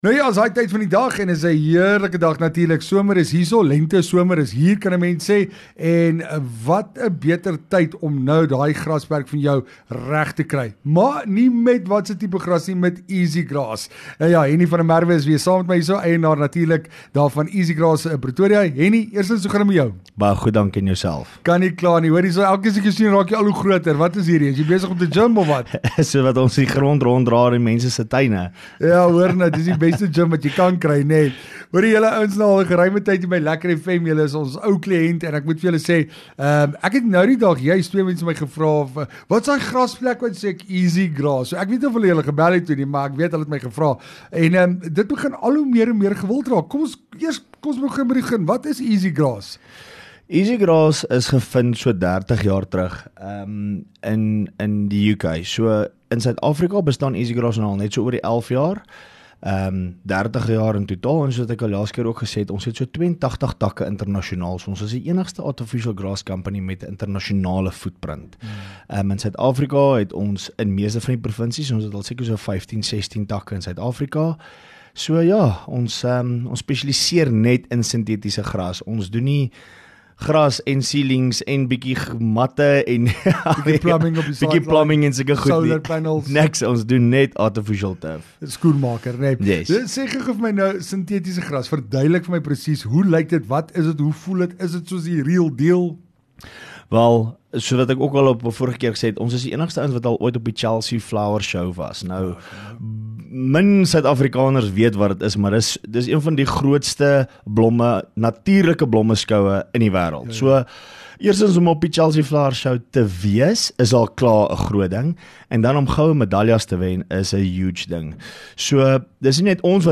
LEKKER FM | Onderhoude 21 Aug Easigrass